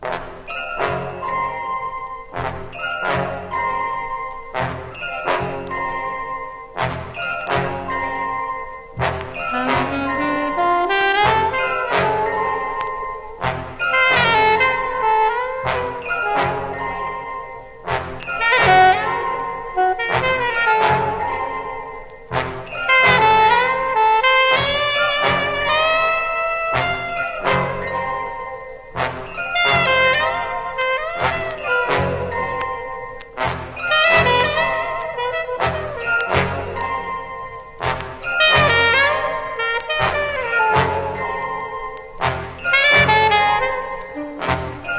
Jazz, Pop, Easy Listening　USA　12inchレコード　33rpm　Mono